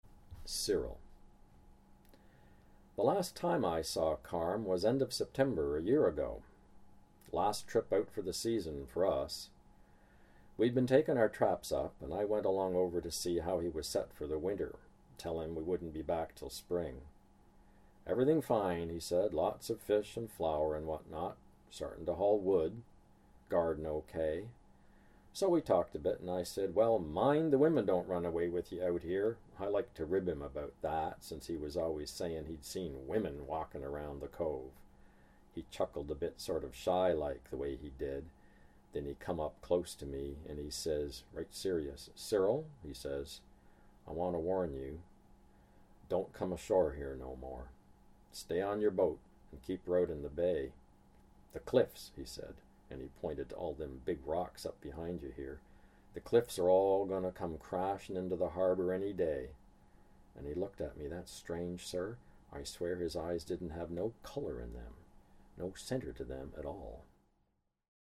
John Steffler reads Cyril [The last time I saw Carm] from The Grey Islands